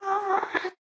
moan2.ogg